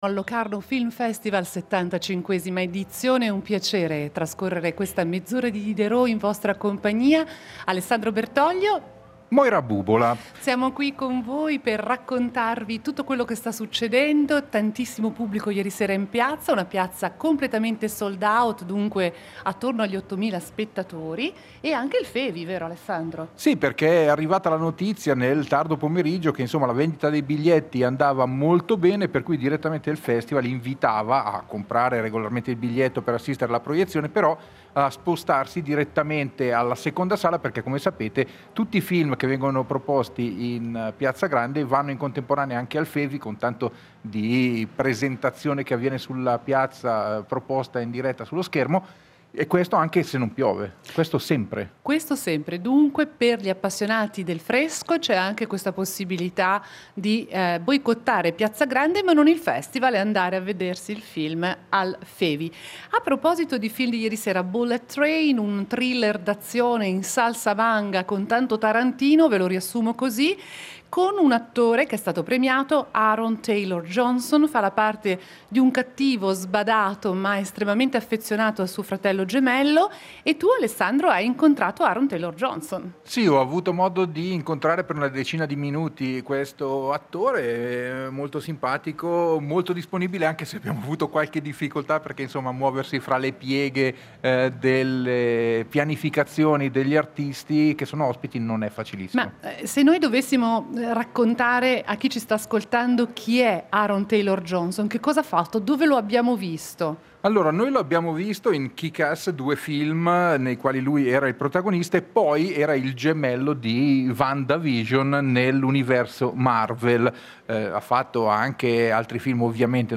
in diretta dalla 75esima edizione del Locarno Film Festival